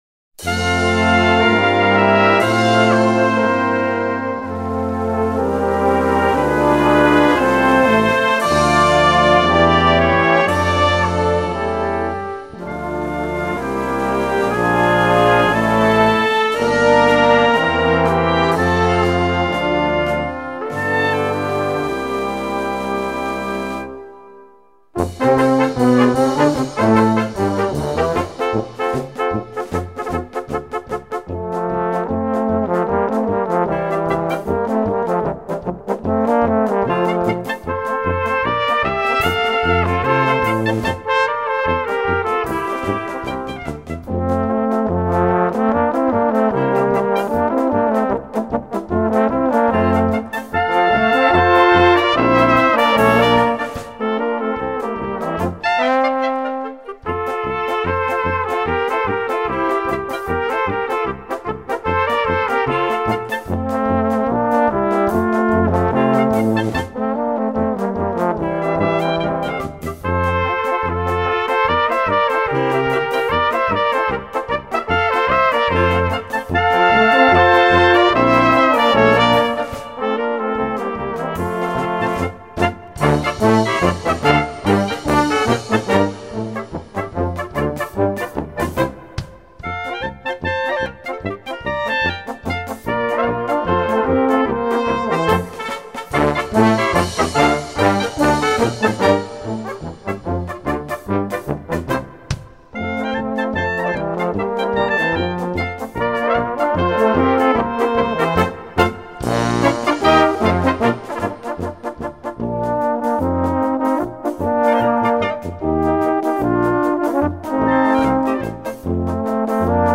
Gattung: Polka
A4 Besetzung: Blasorchester PDF